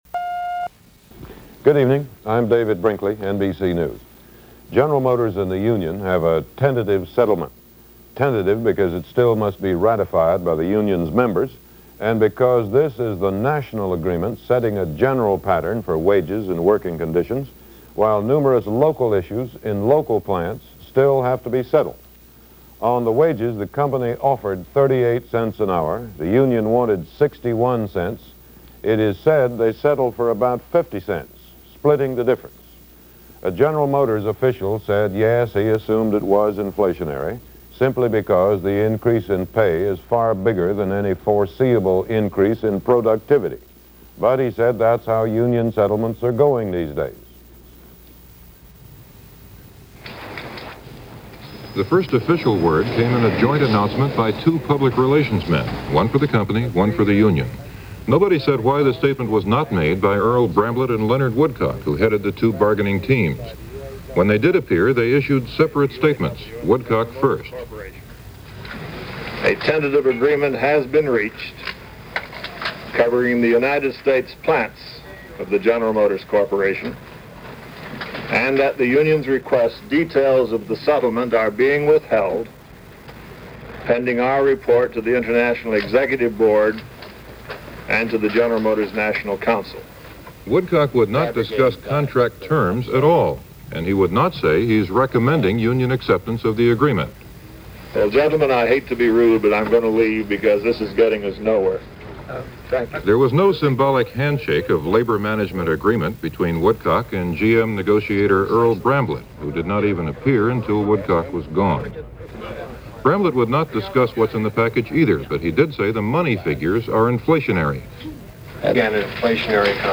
. or click on the link here for Audio Player – NBC Nightly News – November 11, 1970 […]